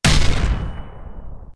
flashbang.mp3